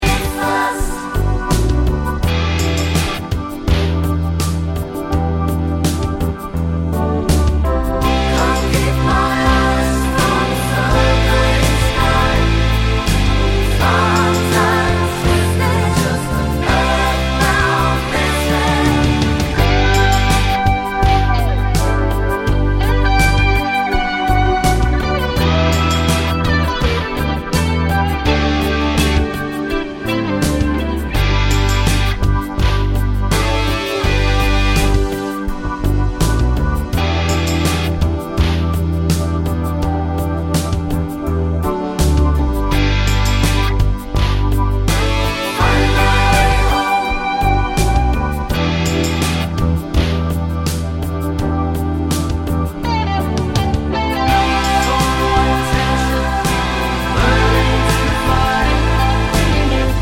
With Spoken Bits On Track Rock 4:57 Buy £1.50